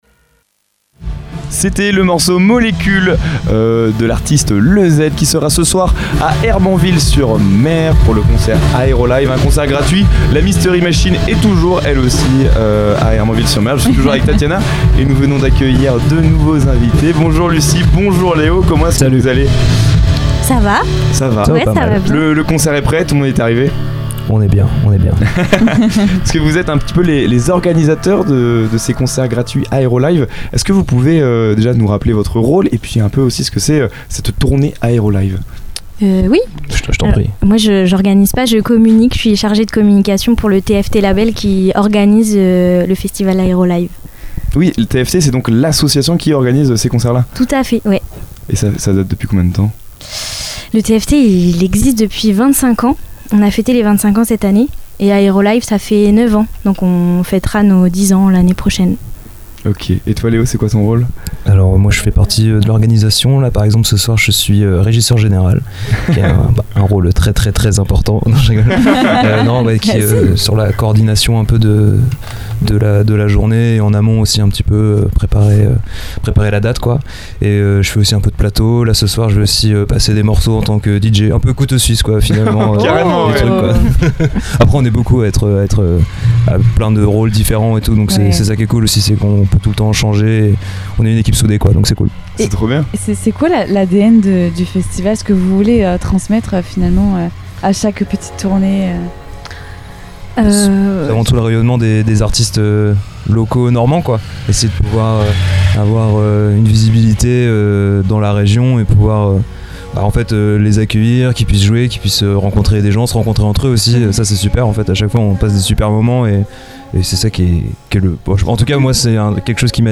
Une interview passionnante qui met en lumière l’engagement et l’énergie des organisateurs, et qui nous plonge dans l’ambiance unique d’Aérolive, devenu un moment incontournable de l’été sur la côte normande.